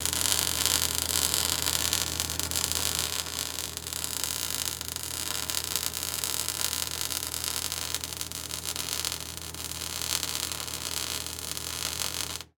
Lyd: Industri fabrikk
Lyd: Sveising
Sveising_industi.mp3